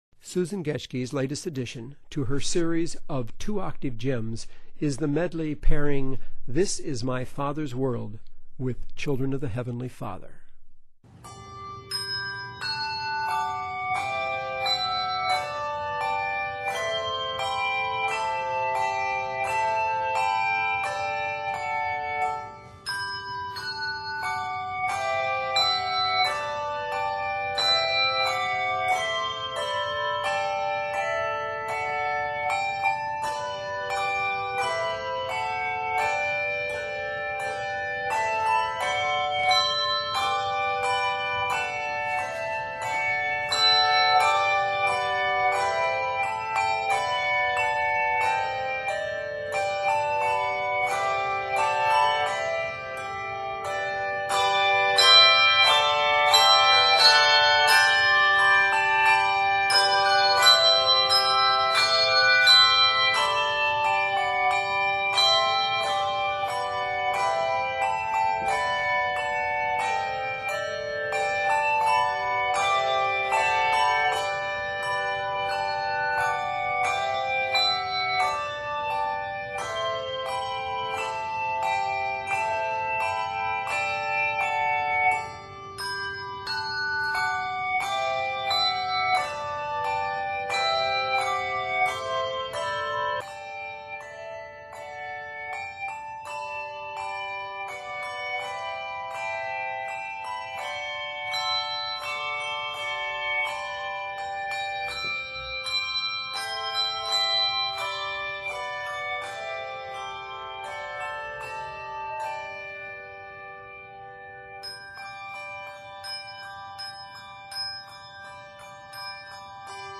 the two octave choir (17 bells)